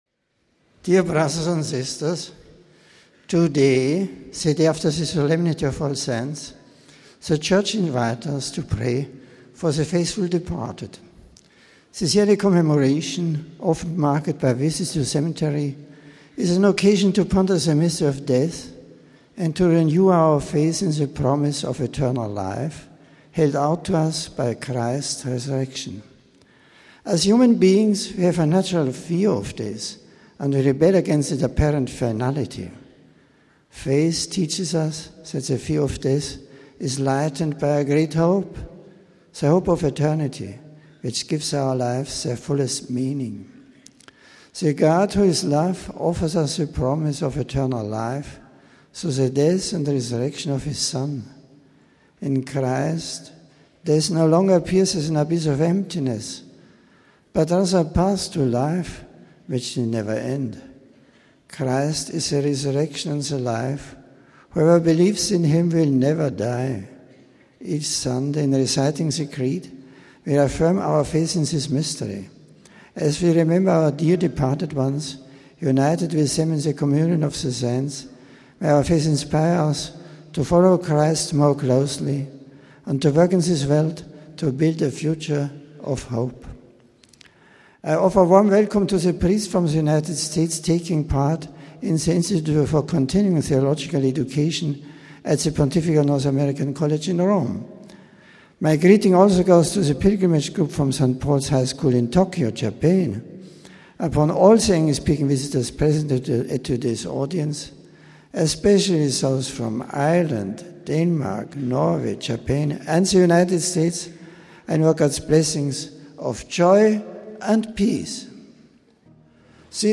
The general audience of Nov. 2, All Souls Day, was held indoors in the Vatican’s Paul VI Audience Hall.
An aide greeted the Pope on behalf of the English speaking pilgrims introducing the various groups to him. Pope Benedict then delivered a discourse in English: